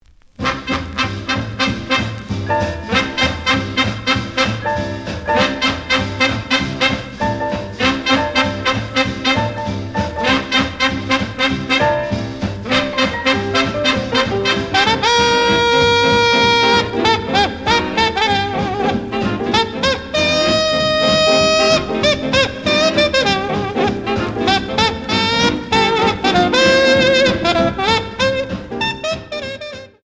The audio sample is recorded from the actual item.
●Format: 7 inch
●Genre: Big Band